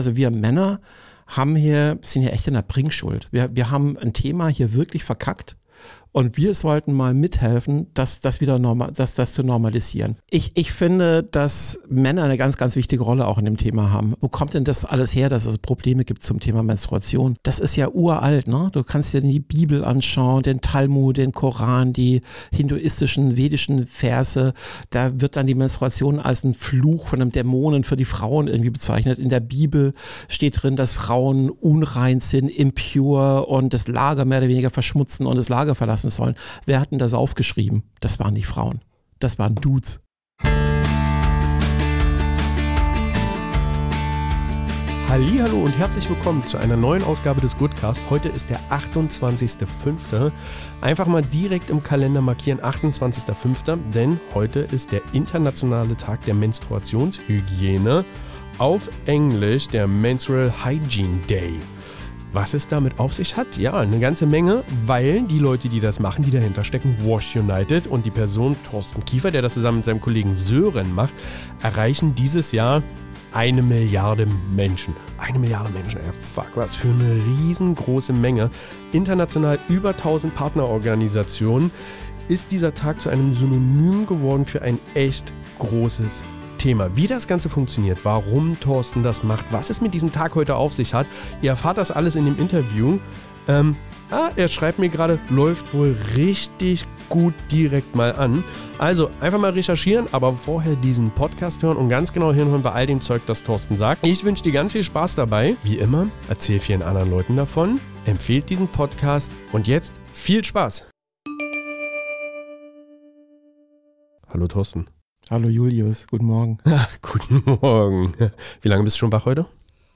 Ein offenes, mutiges Gespräch über Veränderung – und darüber, wie Aufklärung beginnt: mit Haltung, Herz und der Bereitschaft zuzuhören.